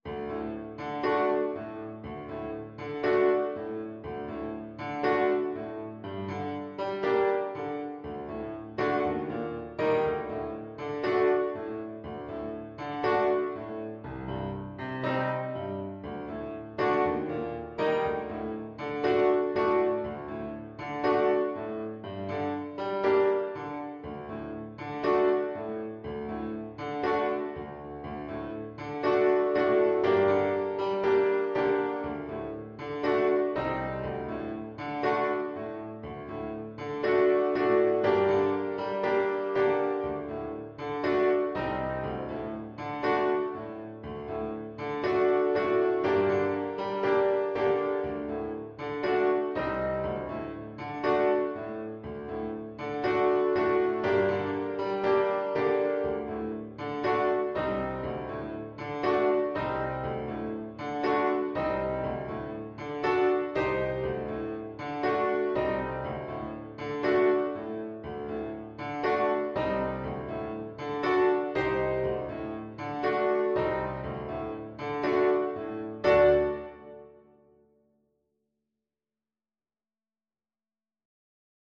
Play (or use space bar on your keyboard) Pause Music Playalong - Piano Accompaniment Playalong Band Accompaniment not yet available transpose reset tempo print settings full screen
4/4 (View more 4/4 Music)
G minor (Sounding Pitch) E minor (Alto Saxophone in Eb) (View more G minor Music for Saxophone )
Allegro moderato =120 (View more music marked Allegro)